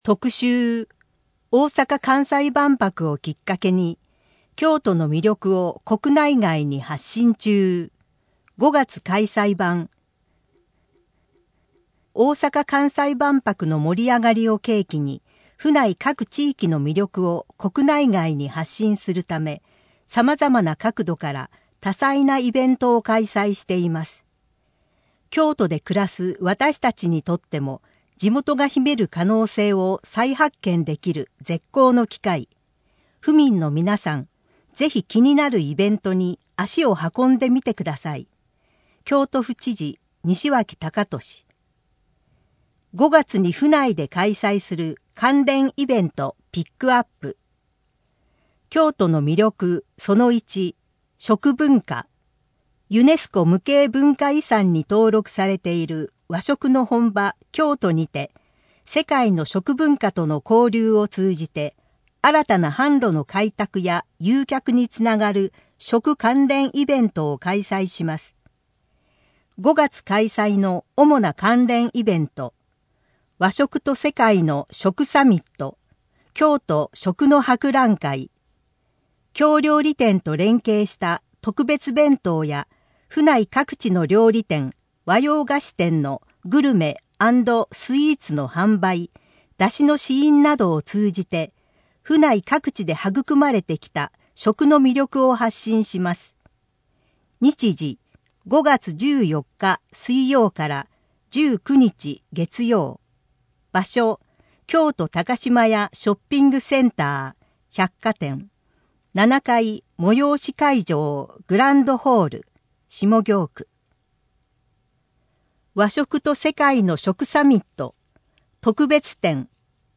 このページの音声版を聞く 音声版ご利用のご案内 特集 大阪・関西万博をきっかけに 京都の魅力を国内外に発信中！